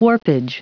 Prononciation du mot warpage en anglais (fichier audio)
Prononciation du mot : warpage